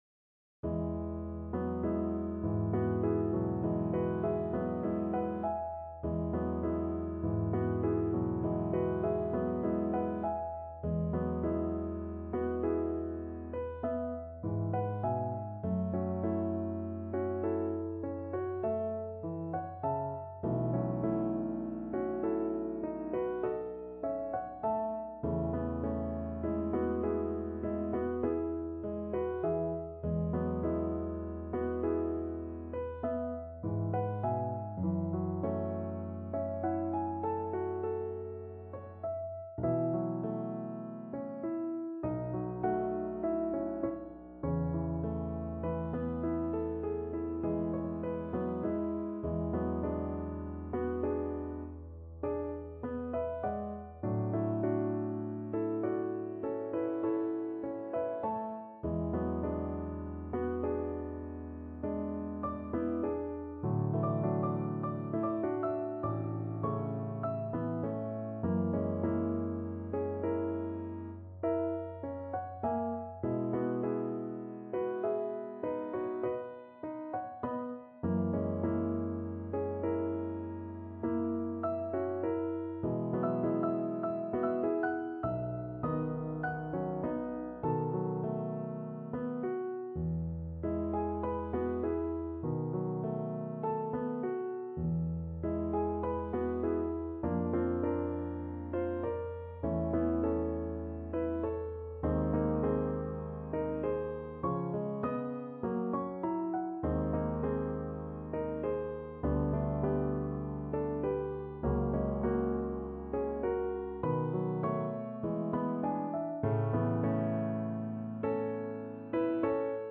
Play (or use space bar on your keyboard) Pause Music Playalong - Piano Accompaniment Playalong Band Accompaniment not yet available transpose reset tempo print settings full screen
E major (Sounding Pitch) (View more E major Music for Violin )
Lento =50
Classical (View more Classical Violin Music)